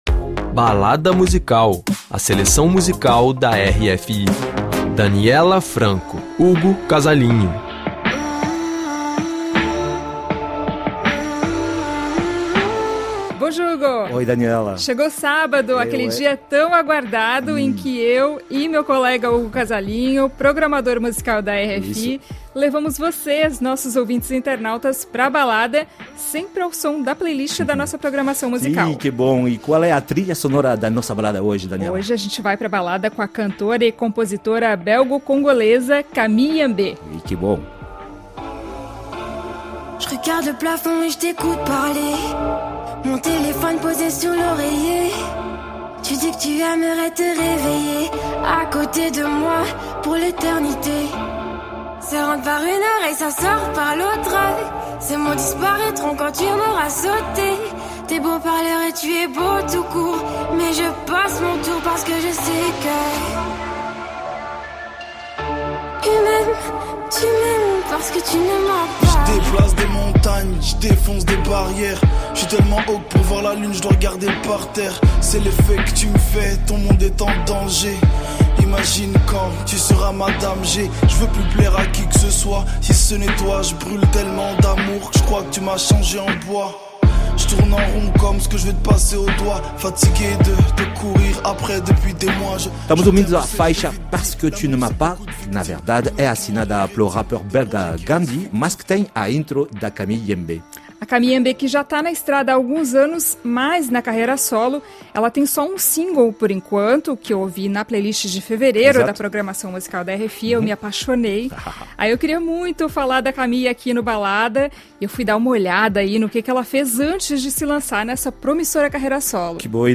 Na primeira faixa homônima, ela aposta em um dream pop sensível e intenso, com letras que debatem o dilema entre o ser e o parecer.